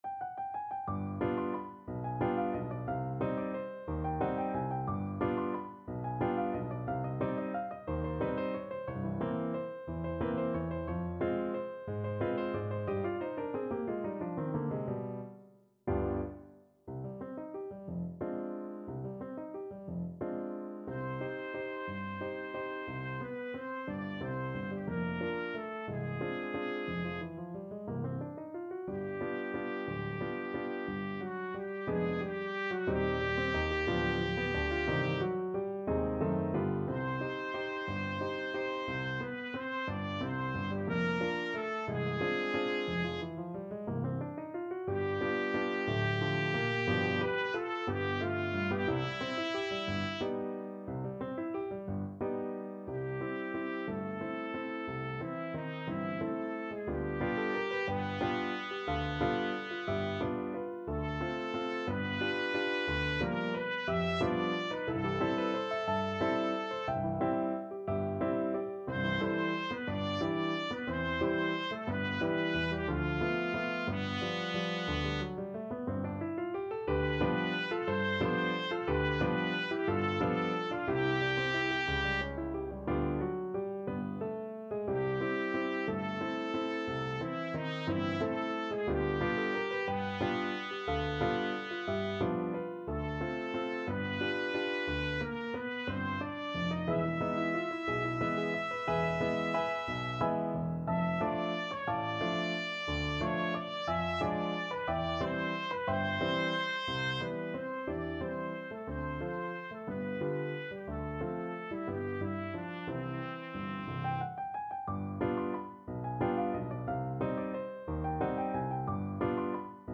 3/4 (View more 3/4 Music)
Allegro movido =180 (View more music marked Allegro)
Trumpet  (View more Easy Trumpet Music)
Classical (View more Classical Trumpet Music)
Mexican